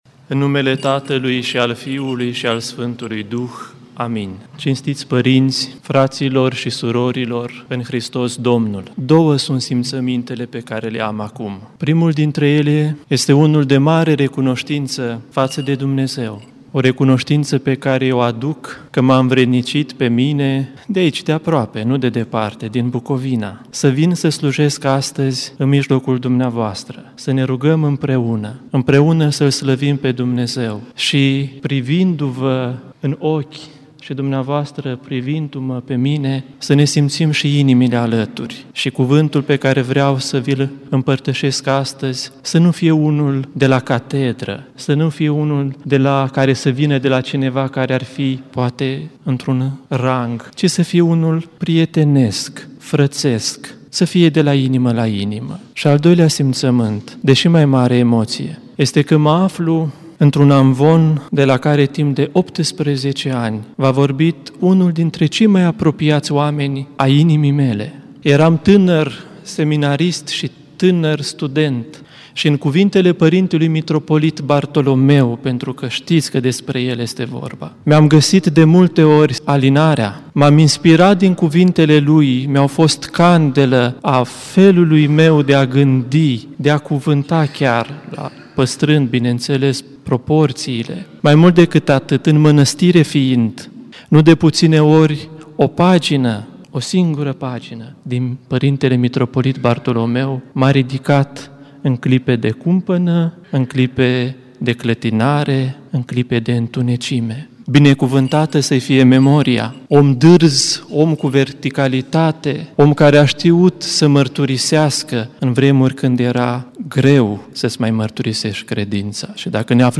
Preasfințitului Damaschin Dorneanul, Episcopul vicar al Arhiepiscopiei Sucevei și Rădăuților a oficiat Sfânta Liturghie în Catedrala Mitropolitană din Cluj.
Predica PS Damaschin Dorneanul: